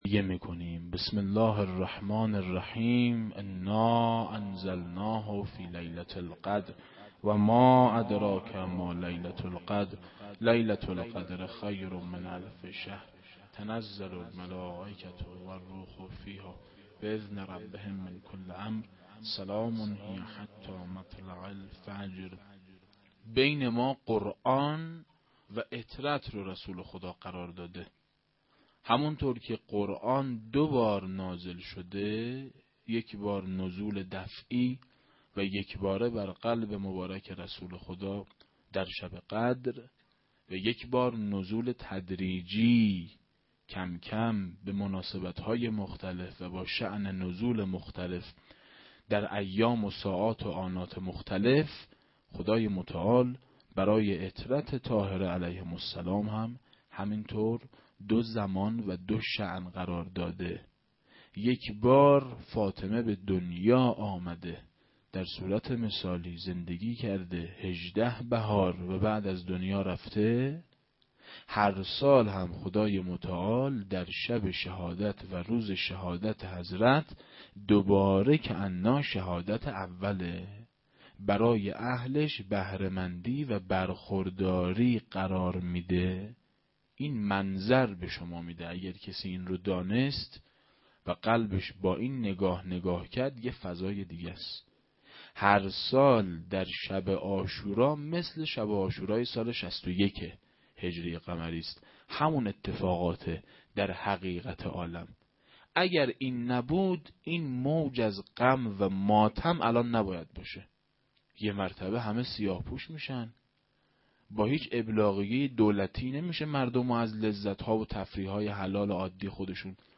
سخنرانی.mp3